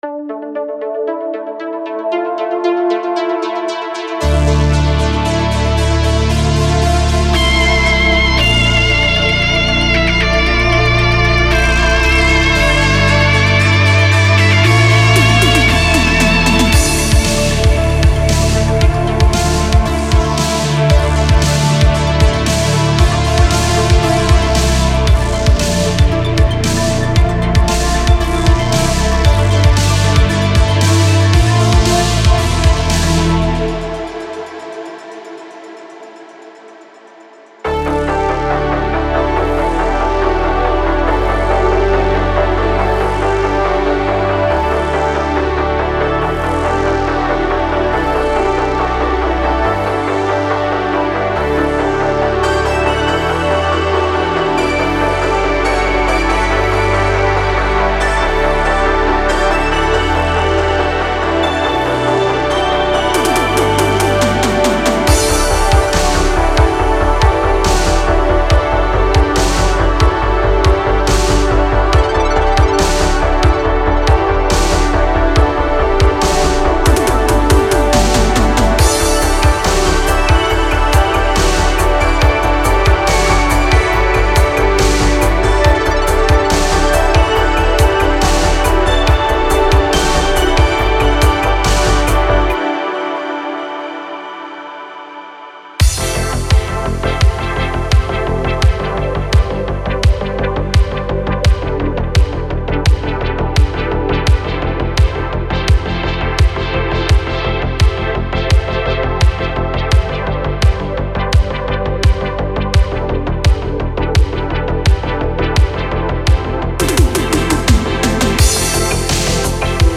Genre: Synthwave / Retrowave